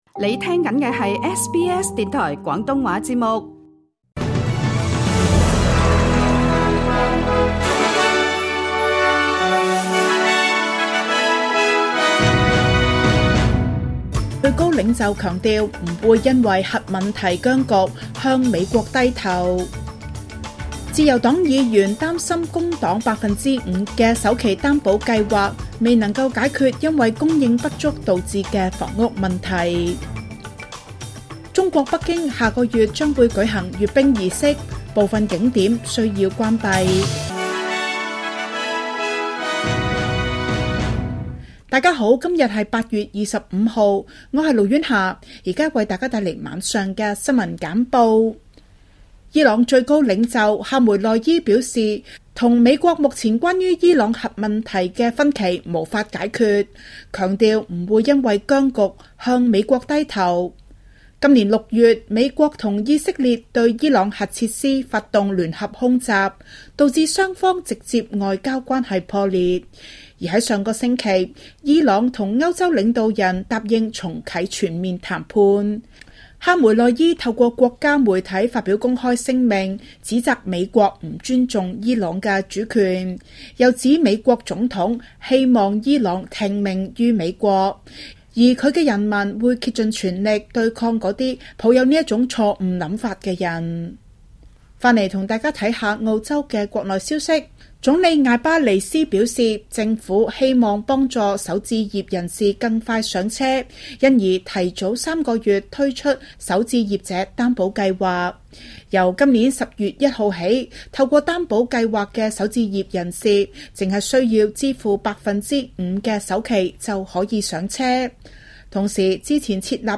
SBS晚間新聞（2025年8月25日）